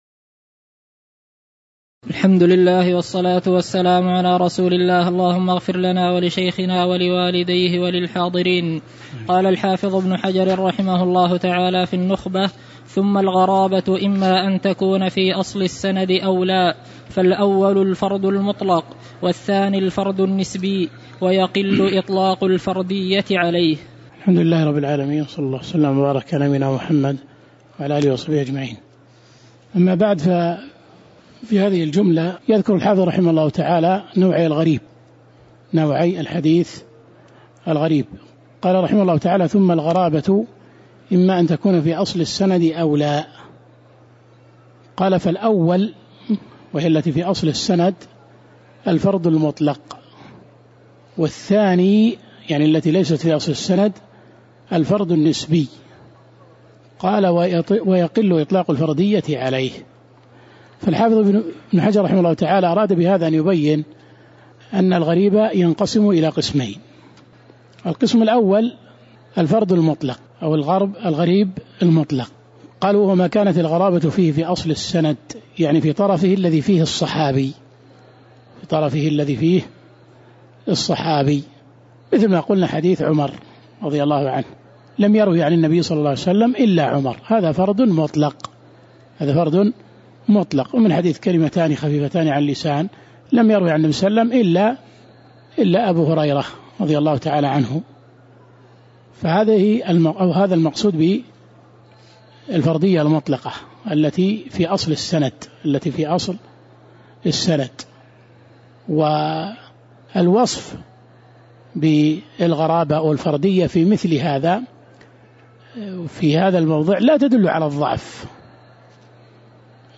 تاريخ النشر ٢٦ محرم ١٤٤٠ هـ المكان: المسجد النبوي الشيخ